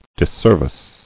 [dis·ser·vice]